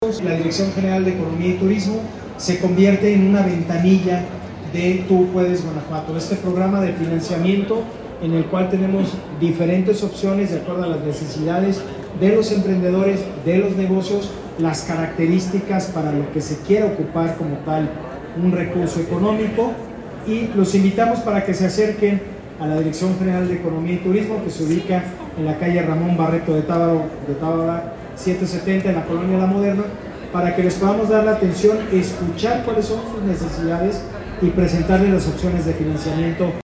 AudioBoletines
Héctor Muñoz Krieger, director de economía y turismo